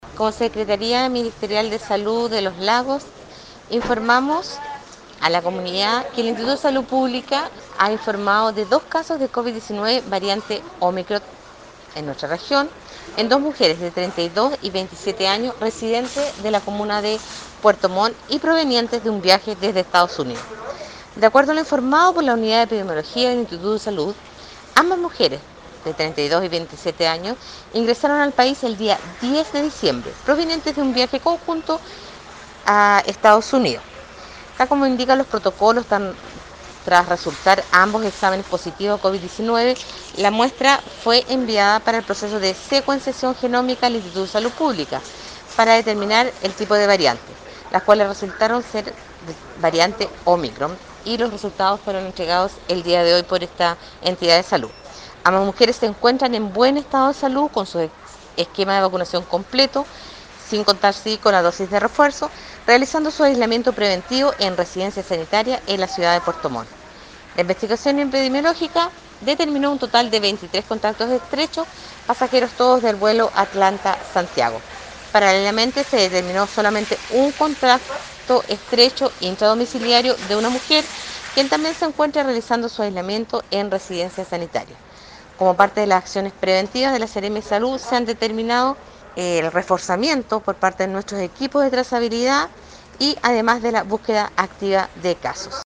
Esto fue lo señalado por la seremi (S) de Salud, Marcela Cárdenas.